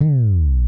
slide - bass.wav